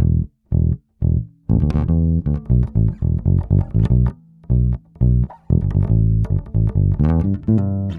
18 Bass PT2.wav